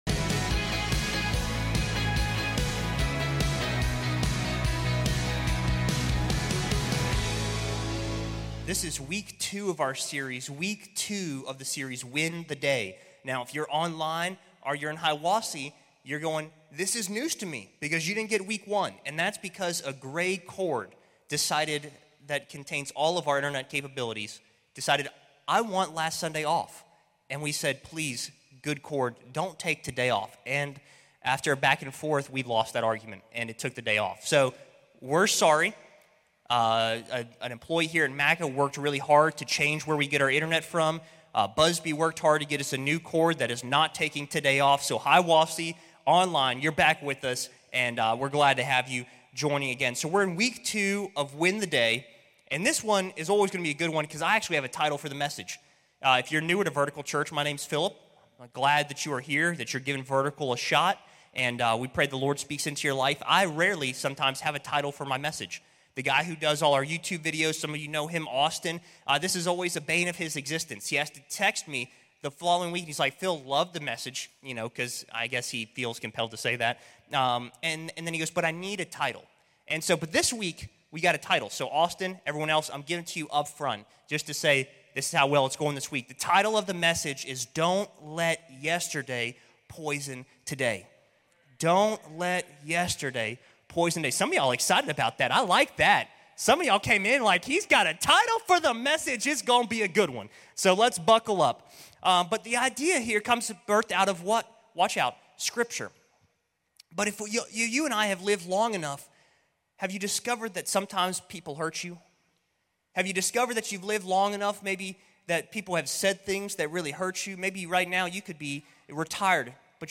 This is week two of our series, "Win the Day?" In this sermon